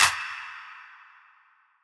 Metro Claps [Thin].wav